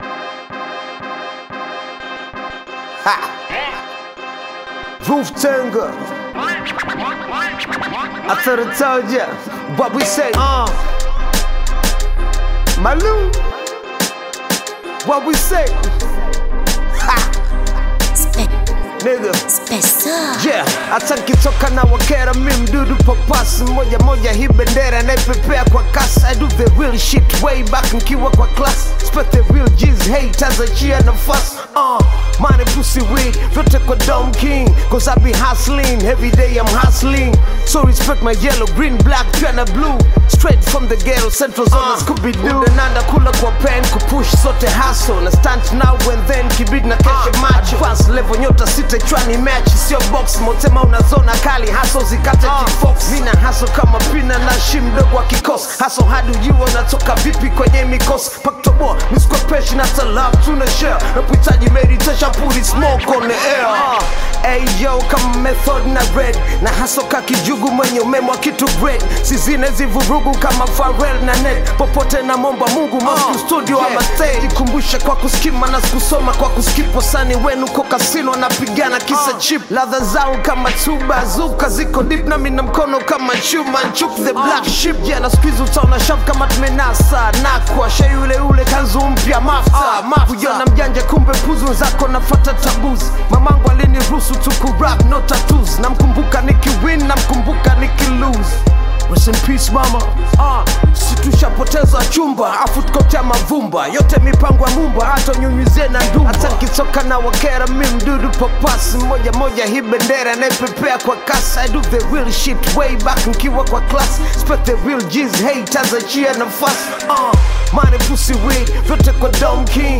Tanzanian rapper